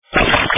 electrohaus/electroclash